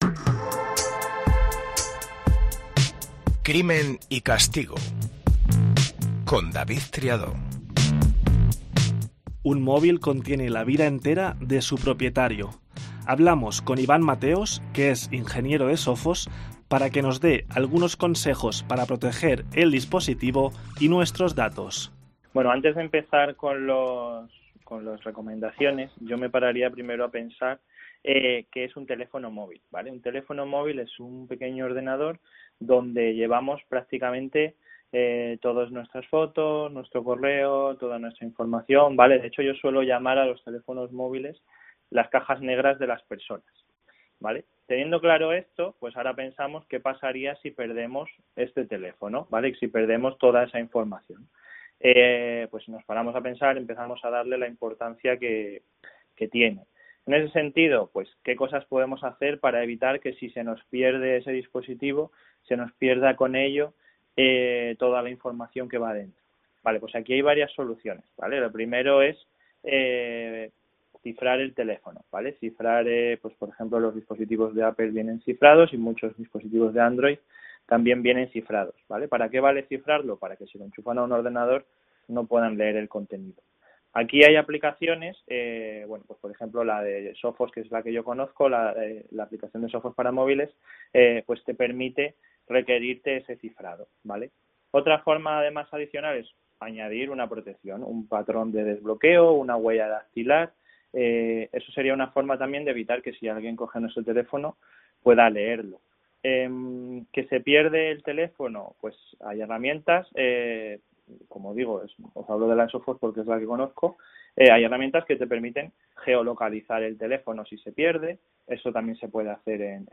Lo hace en un formato dinámico, fresco, cercano, y con entrevistas a los implicados e intervenciones de expertos.